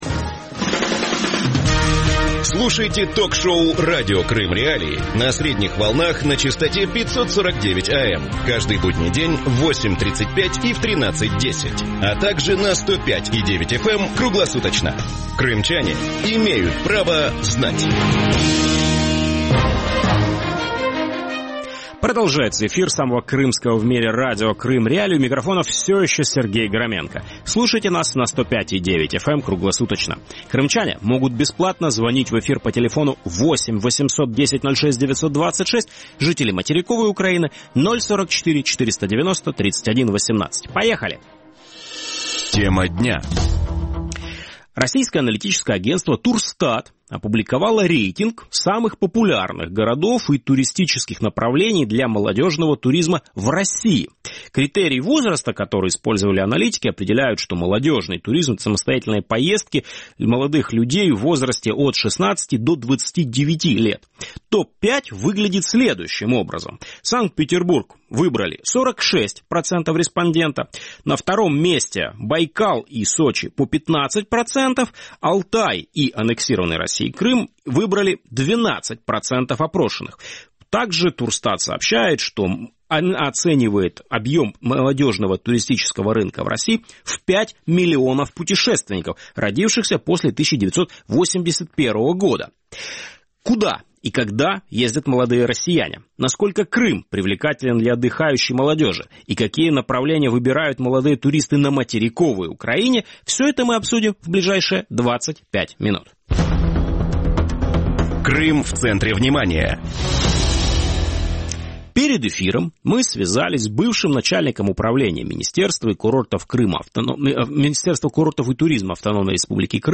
Куда и когда ездят молодые россияне? Насколько Крым привлекателен для отдыхающей молодежи? И какие направления выбирают молодые туристы на материковой Украине? Гости эфира